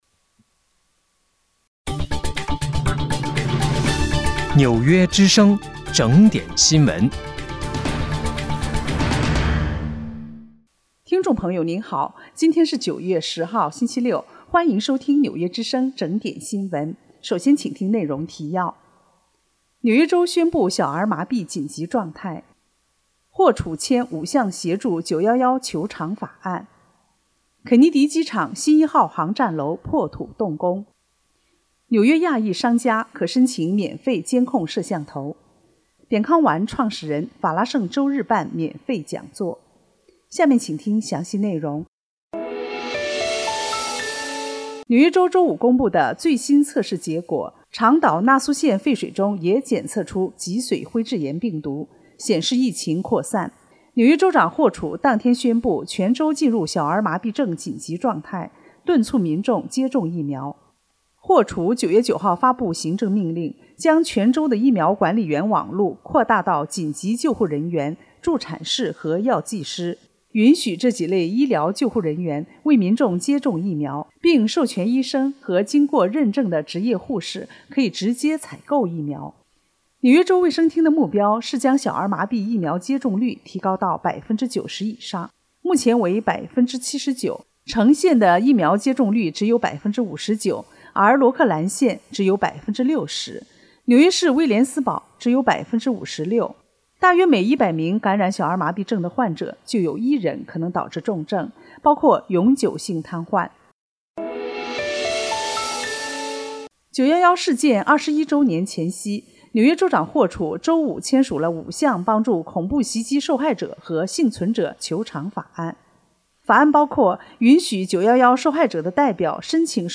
9月10日（星期六）纽约整点新闻